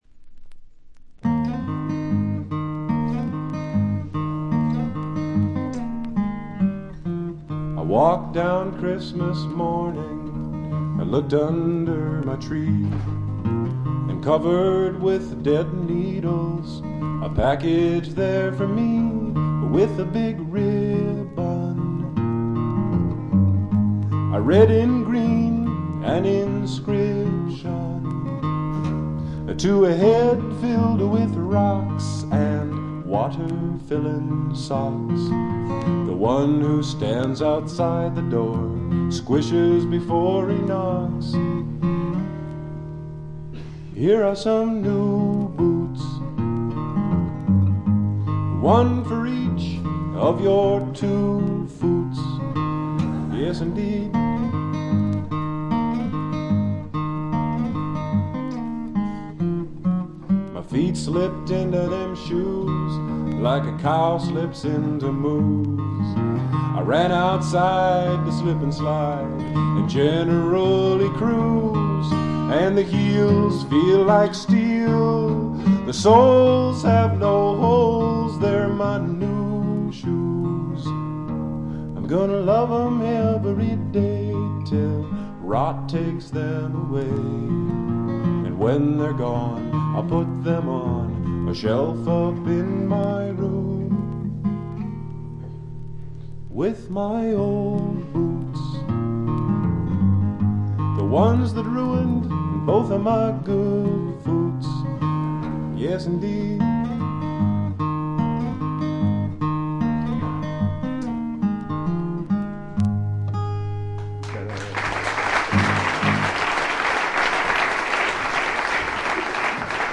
細かなチリプチのみ。
試聴曲は現品からの取り込み音源です。